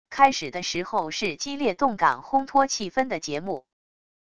开始的时候是激烈动感烘托气氛的节目wav音频